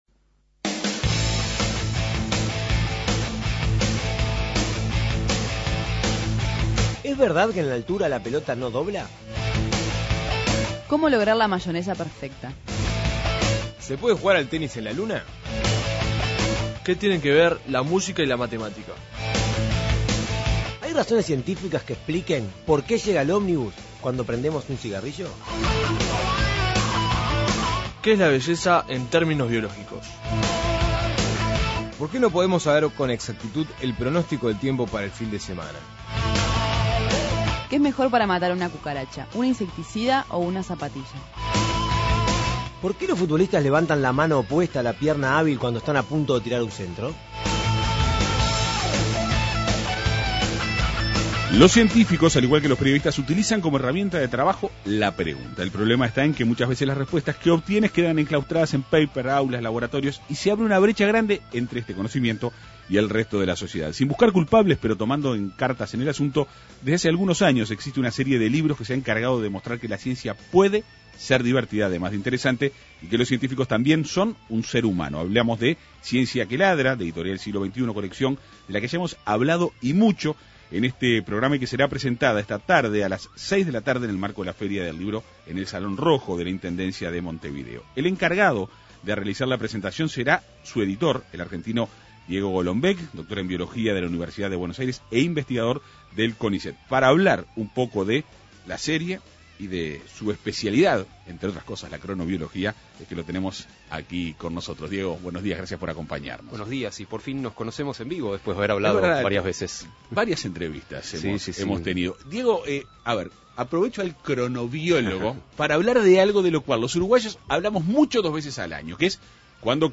El autor, Diego Golombek, dialogó en la Segunda Mañana de En Perspectiva.